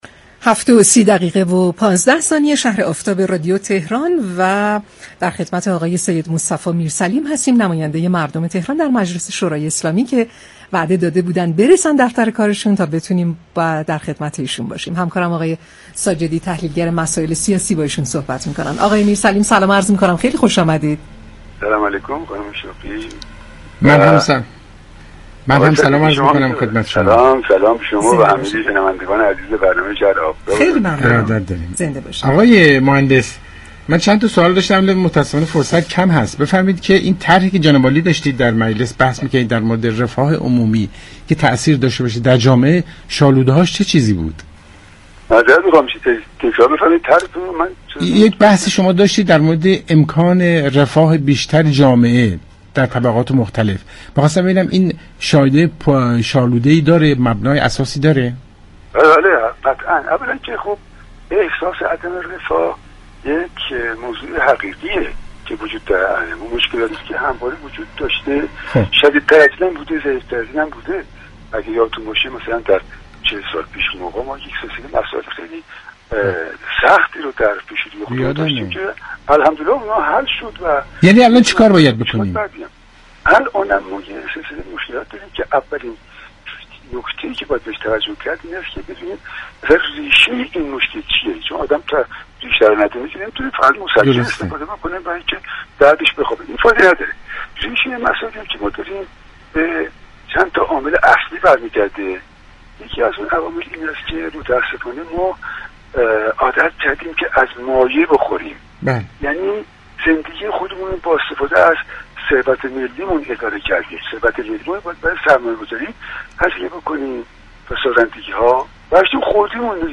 به گزارش پایگاه اطلاع رسانی رادیو تهران، مصطفی میرسلیم عضو مجمع تشخیص مصلحت نظام در گفتگو با برنامه شهر آفتاب رادیو تهران گفت: مشكلات كشور باید ریشه‌یابی شود زیرا تا زمانی كه مشكلات به صورت ریشه‌ای حل نشود هیچ مشكلی حل شدنی نخواهد بود.